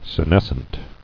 [se·nes·cent]